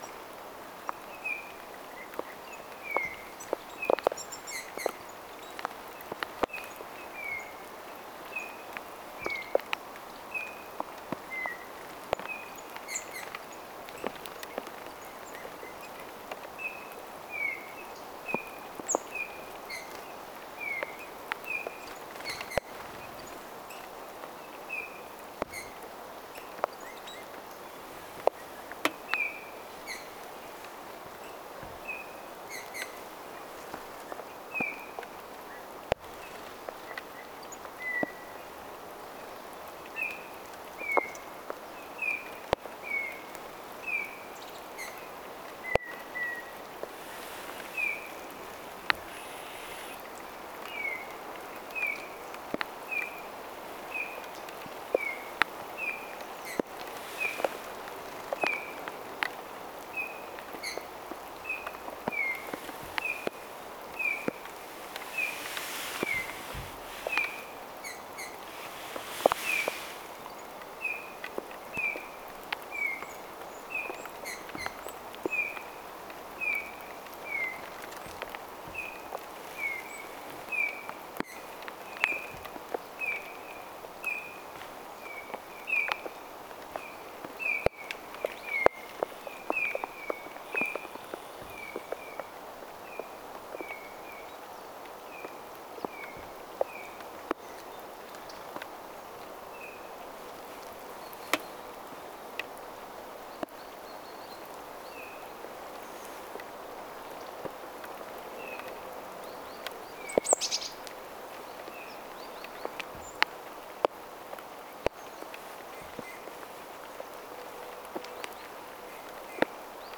tuollaista punatulkkujen ääntelyä
tuollaista_punatulkkujen_aantelya.mp3